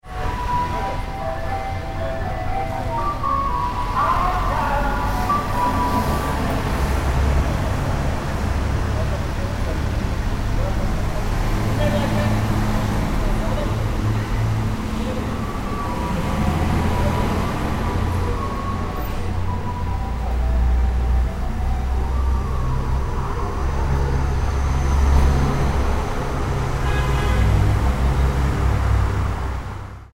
Mesela, benim sevdiğim sesleri ‘Aygaz’